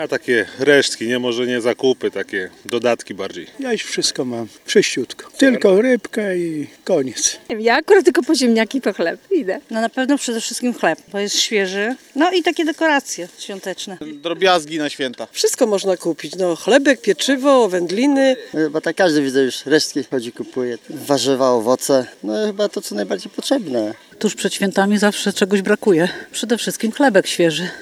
Chcemy, żeby na wigilię wszystko było świeże – mówią kupujący na ryneczku zielonogórzanie: